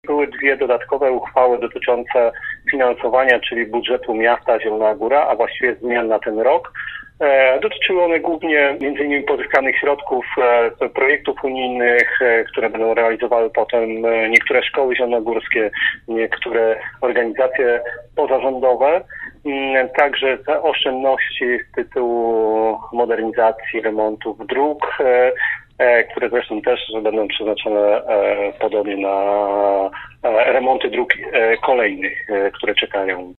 Pierwsza zmieniała uchwałę budżetową na ten rok, druga dotyczyła wieloletniej prognozy finansowej. Tłumaczy przewodniczący Rady Miasta – Piotr Barczak: